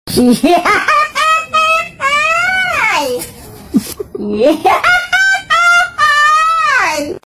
hihihahahayy meme sound effect
hihihahahayy-meme-sound-effect.mp3